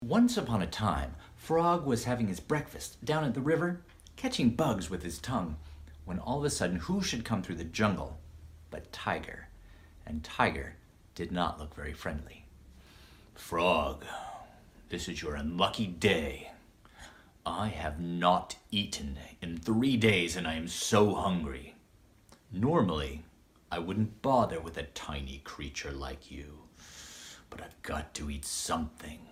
33 Years of Tuesday Night Storytelling